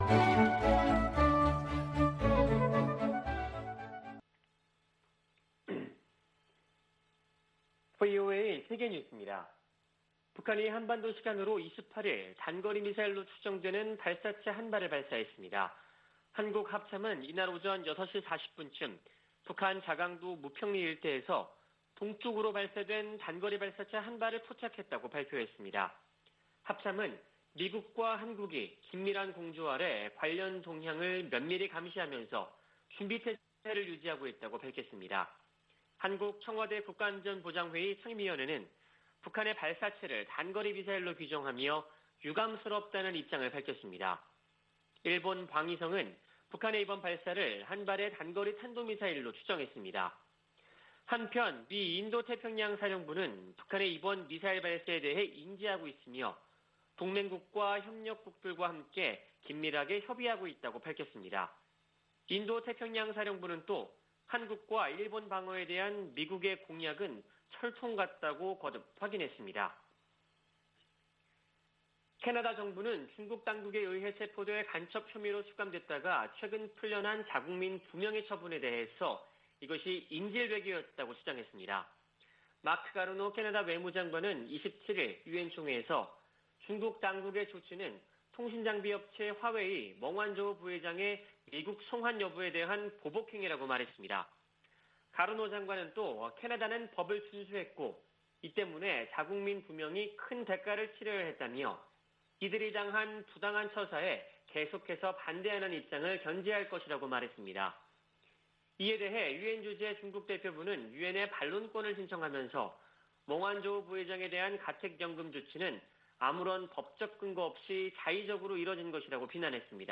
VOA 한국어 아침 뉴스 프로그램 '워싱턴 뉴스 광장' 2021년 9월 26일 방송입니다. 북한은 김여정 노동당 부부장이 대남 유화 담화를 내놓은 지 사흘 만에 단거리 미사일 추정 발사체를 동해 쪽으로 발사했습니다. 미 국무부는 북한의 발사체 발사를 규탄했습니다. 유엔주재 북한 대사가 미국에 대북 적대시 정책을 철회하라고 요구했습니다.